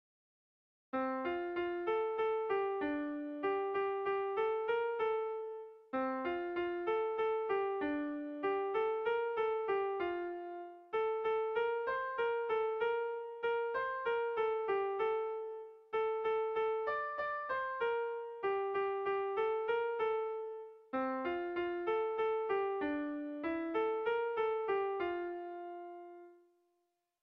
Erlijiozkoa
A1A2BDA2